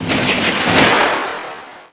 FIREBALL.WAV